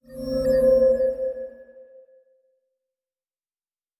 pgs/Assets/Audio/Sci-Fi Sounds/Doors and Portals/Teleport 10_1.wav at 7452e70b8c5ad2f7daae623e1a952eb18c9caab4
Teleport 10_1.wav